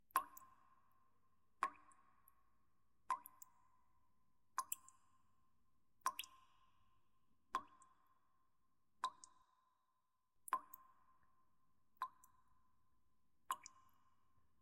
Капающая вода плюс реверберация
kapayushaya_voda_plyus_reverberaciya_hn0.mp3